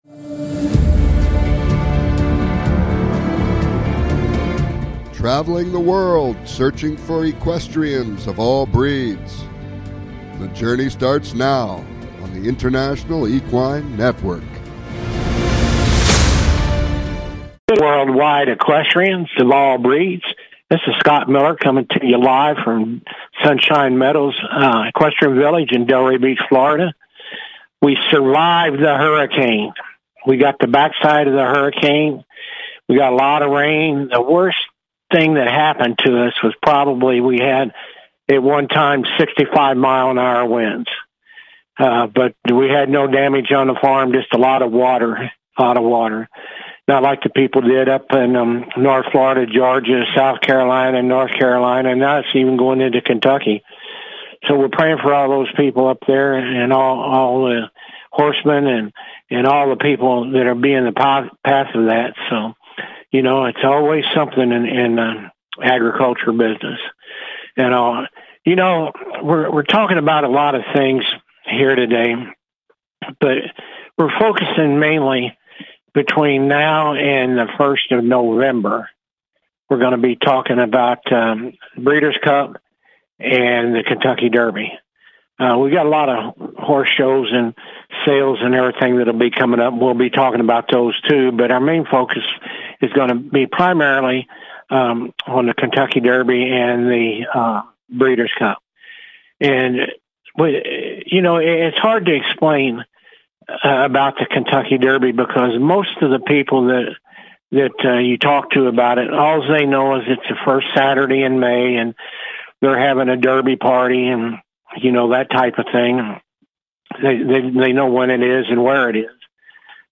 This show will bring you the inside scoop! Calls-ins are encouraged!